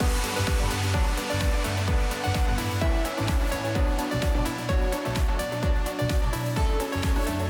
Original 4 Barsbr
There’s quite a complex melody going on there…